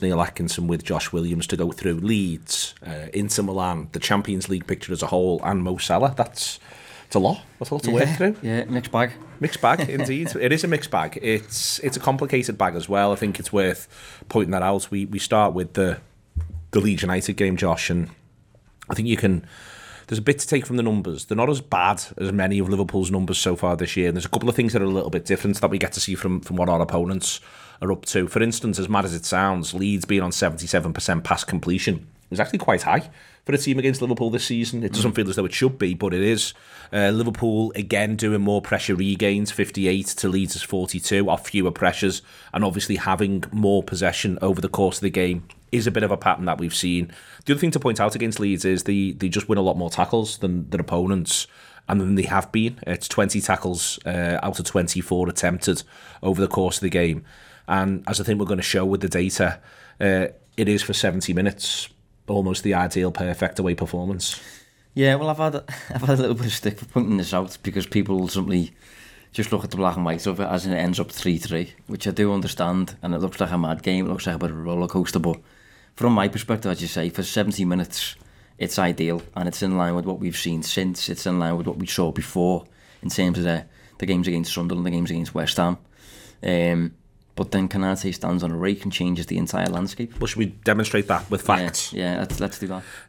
Below is a clip from the show – subscribe for more on Liverpool v Inter Milan…